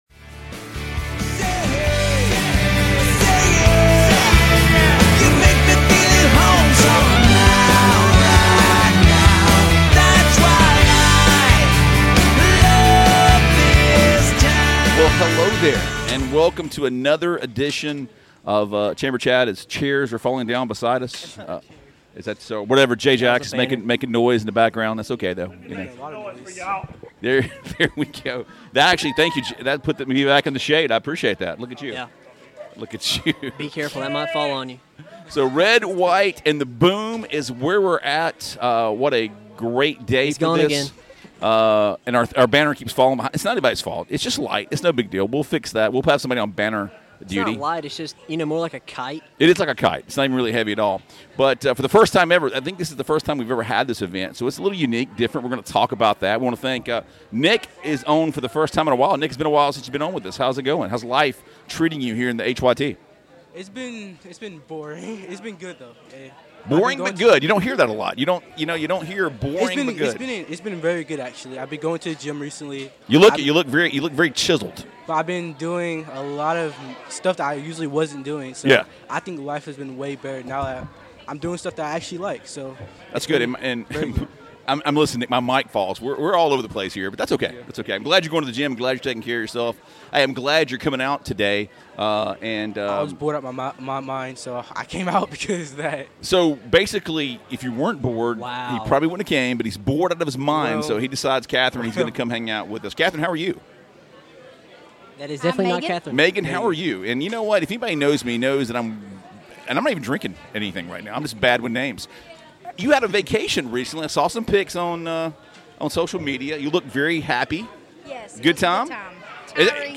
Live from Red, White, and Boom